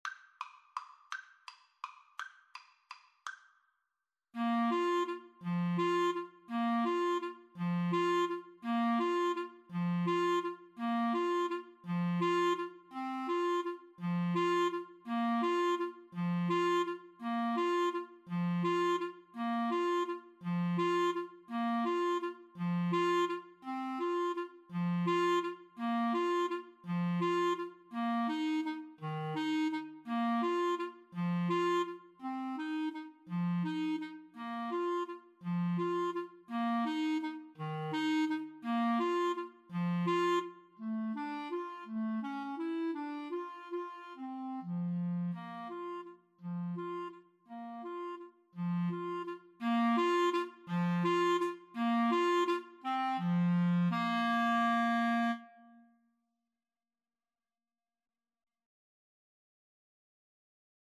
3/4 (View more 3/4 Music)
Tempo di valse =168
Clarinet Duet  (View more Easy Clarinet Duet Music)
Classical (View more Classical Clarinet Duet Music)